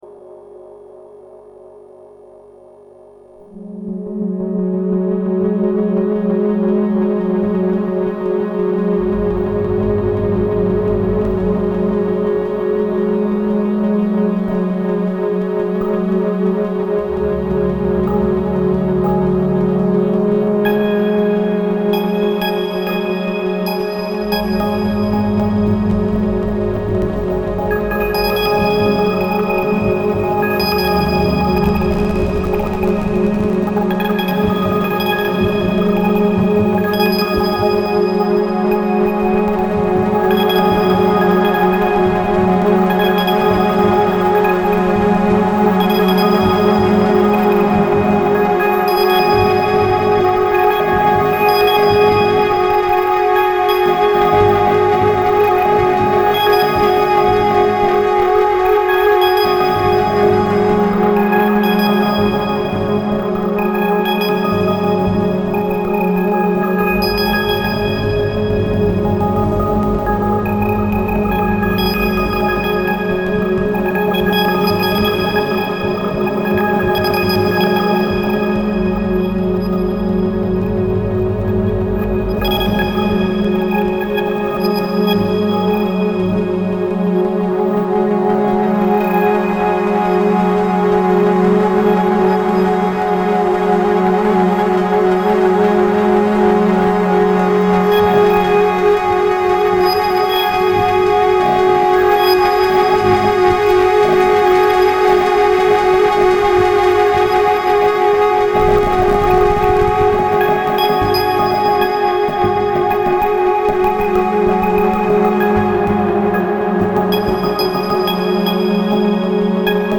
Bon bah j'ai fait ça comme un gros sagouin en une seule prise. J'ai foutu le Mozzi en mode séquence sur une seule note et j'ai joué avec les autres paramètres.